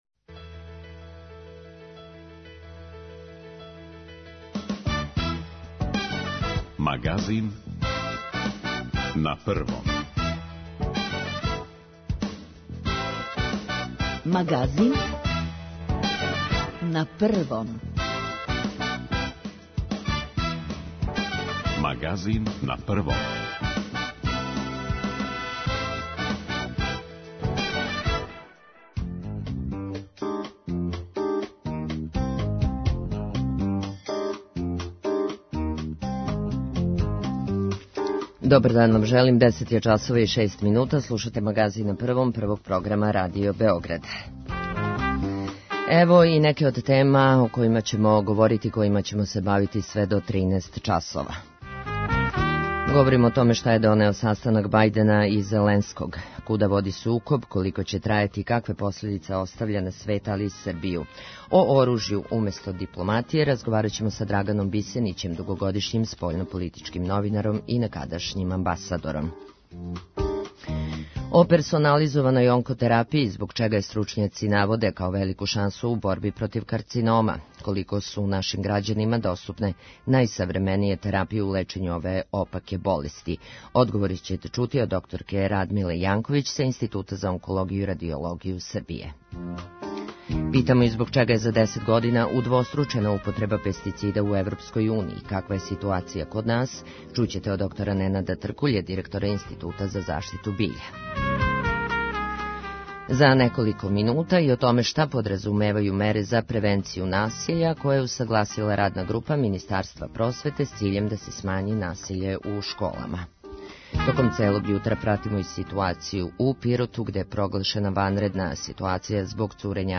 Куда води сукоб, колико ће трајати и какве последице оставља на свет, али и на Србију? Наш саговорник је Драган Бисенић, дугогодишњи спољнополитички новинар, публициста и некадашњи амбасадор.